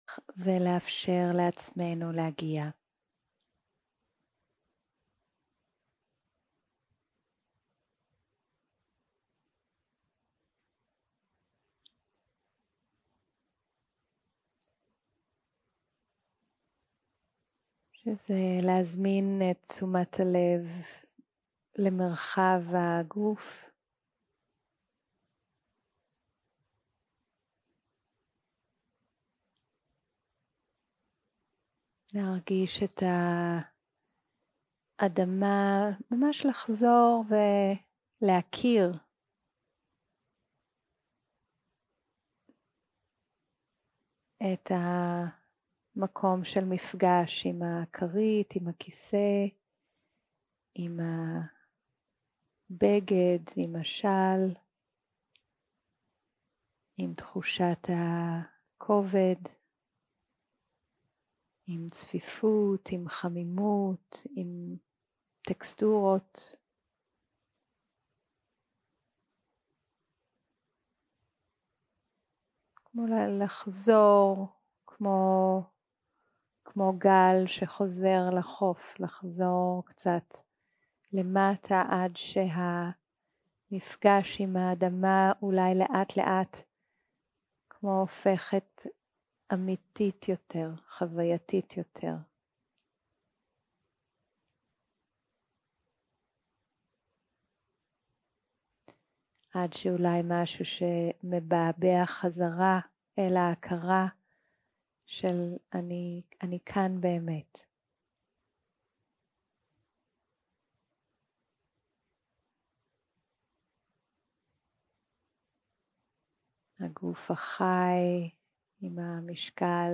יום 1 - הקלטה 1 - ערב - מדיטציה מונחית - יציבות ותחושות גוף 0:00 0:00 סוג ההקלטה: Dharma type: Guided meditation שפת ההקלטה: Dharma talk language: Hebrew